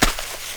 SNEAK DIRT 4.WAV